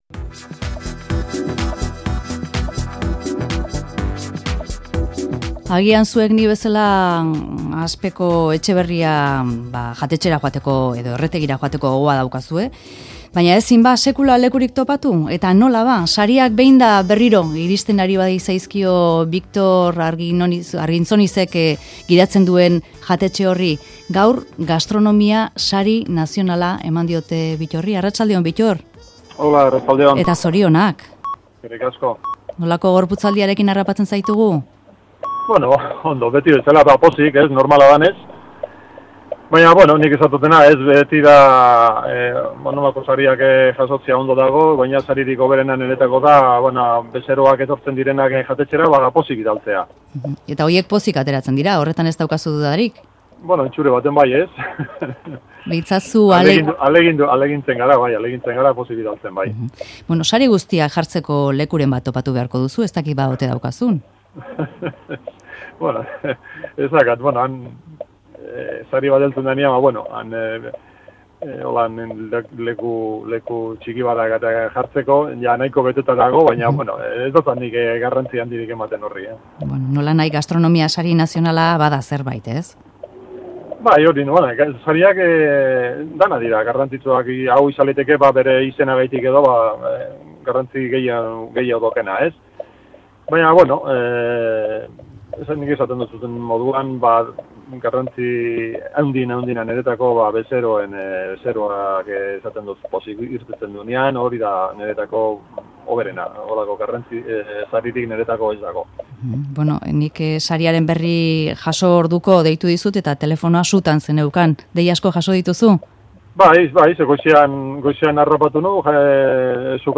Bittor Arginzonizi elkarrizketa Gastronomia Sari Nazionala irabazita